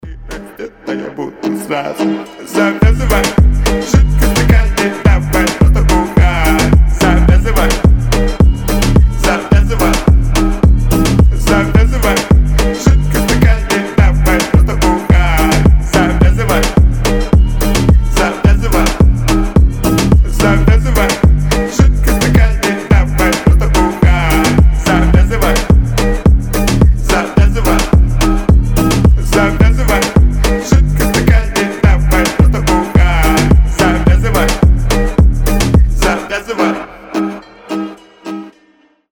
• Качество: 128, Stereo
громкие
русский рэп
веселые